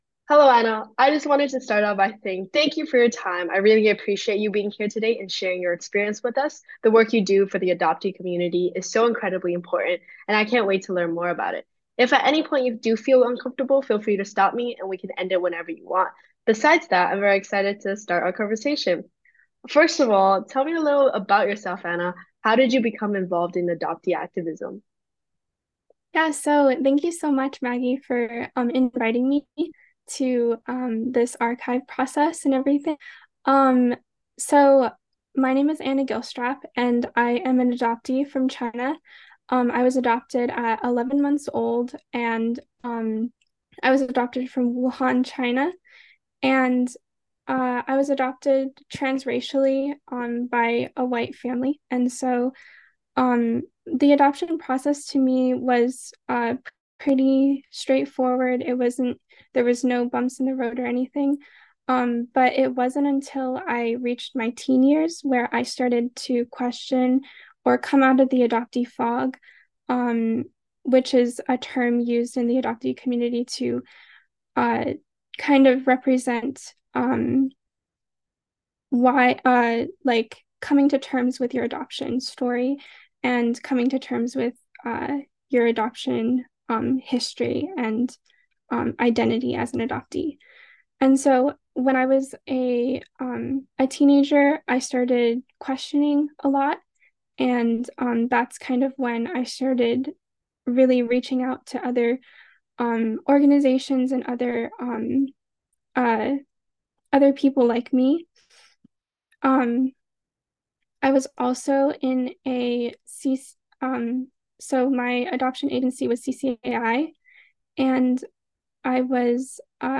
An interview
conducted via Zoom